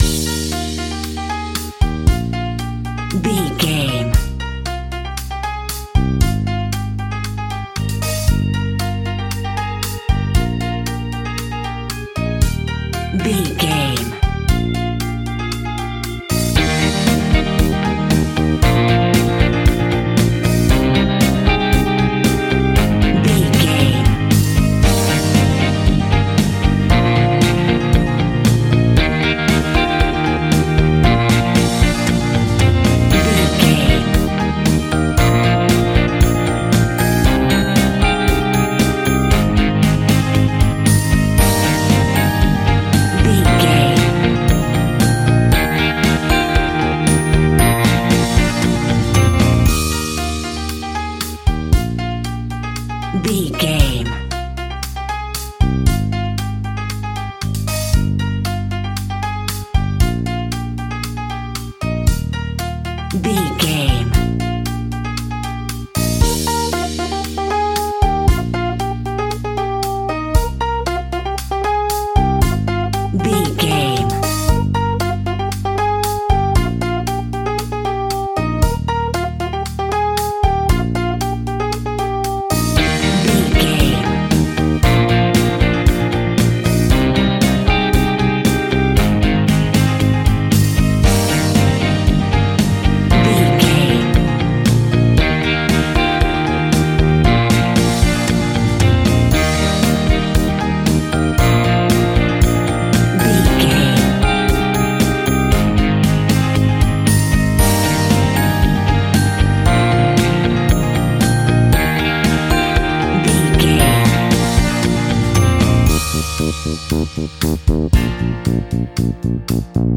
Happy Cheesy Pop Music Theme.
Ionian/Major
E♭
Teen pop
bubblegum
electro pop
synth pop
peppy
upbeat
bright
bouncy
drums
bass guitar
electric guitar
keyboards
hammond organ
acoustic guitar
percussion